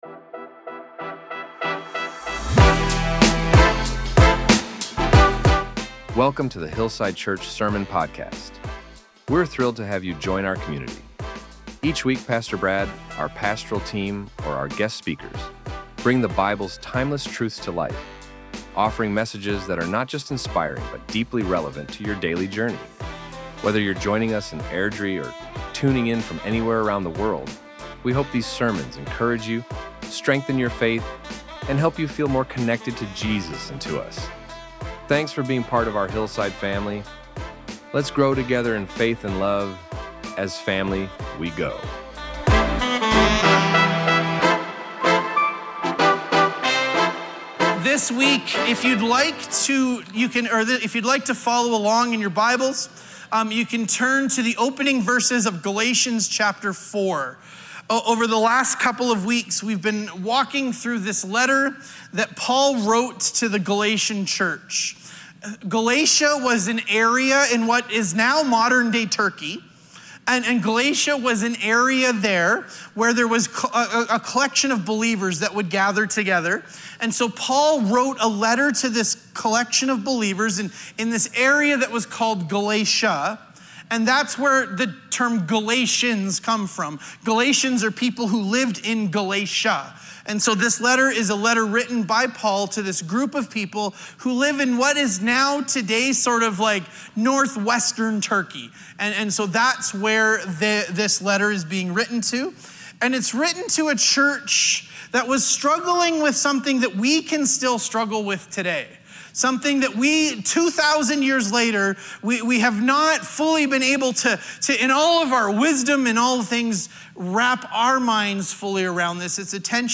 In this week’s sermon, we saw how Paul teaches that grace gives us a new identity, allowing us to call God ‘Abba, Father’ and live in the freedom of already being His children. 3 Takeaways1.